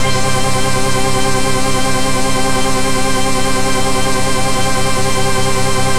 Index of /90_sSampleCDs/Trance_Explosion_Vol1/Instrument Multi-samples/Scary Synth
C4_scary_synth.wav